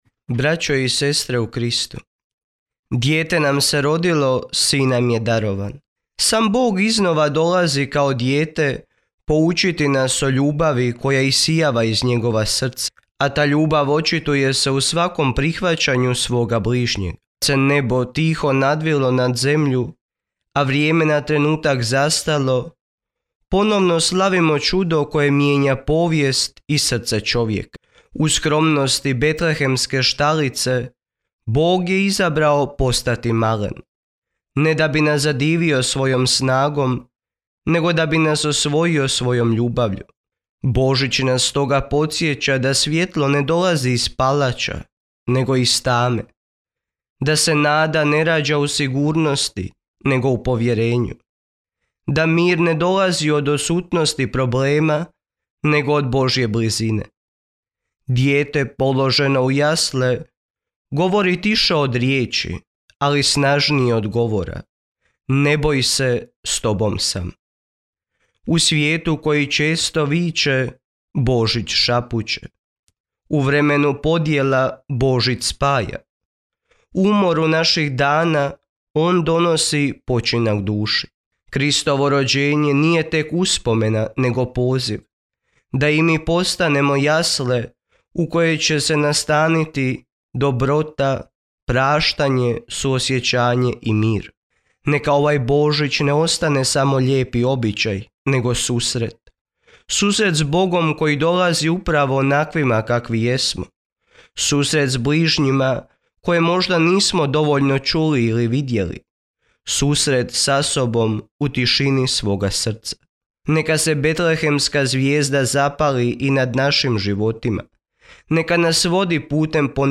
uputio je božićnu poruku svim građanima i župljanima povodom blagdana Božića.